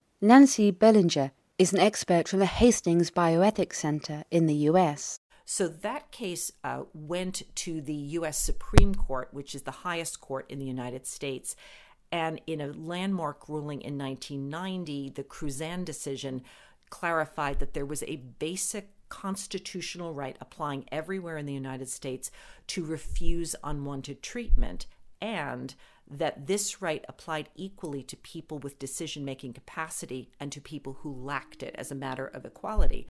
Injured 1983, final court judgment 1990. Commentary